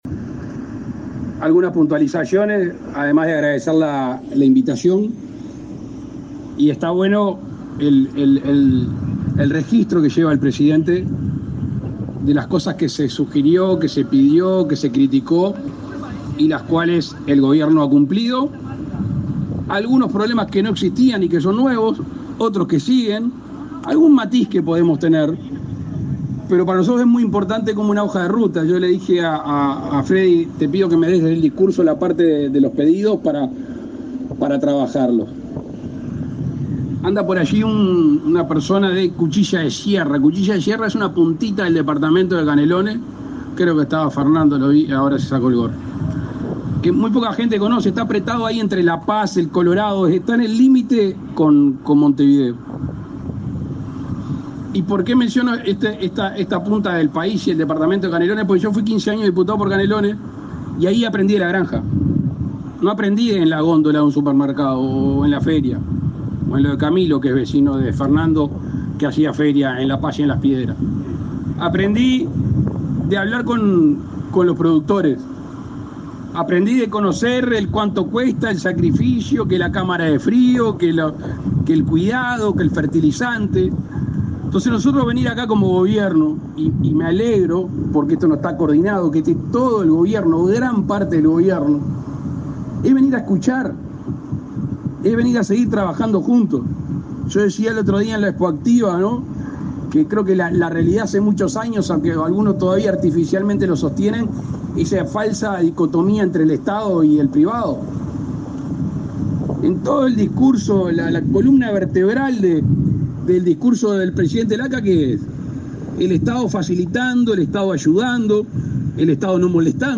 Palabras del presidente Lacalle Pou
El presidente Luis Lacalle Pou encabezó, este jueves 18, la inauguración de la cosecha de arroz en el departamento de Cerro Largo.